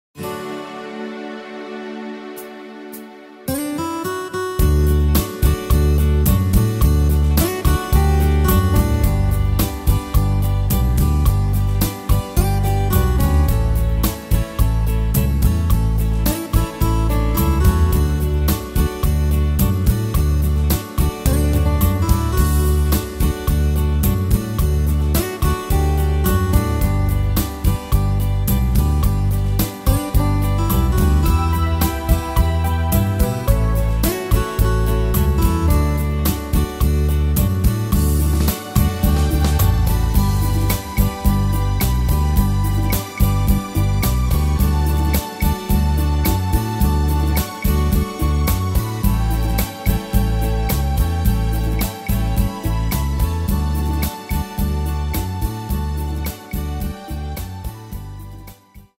Tempo: 108 / Tonart: D-Dur